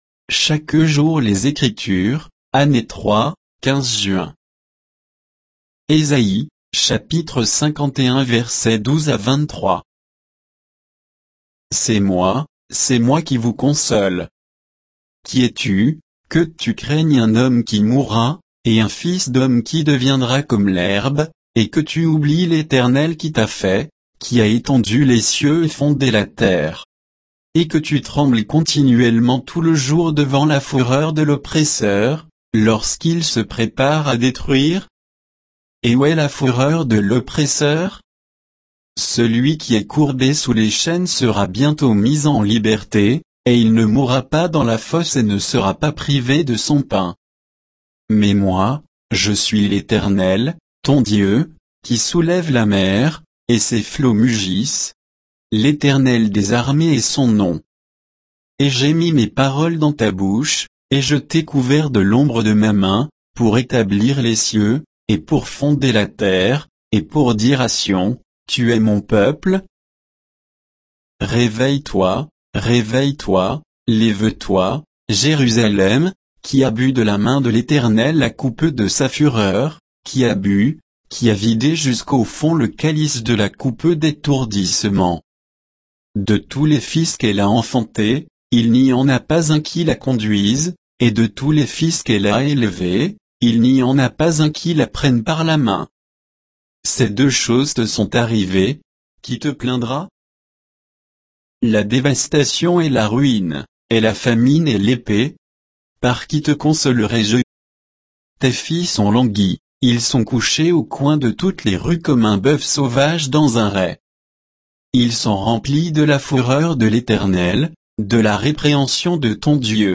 Méditation quoditienne de Chaque jour les Écritures sur Ésaïe 51, 12 à 23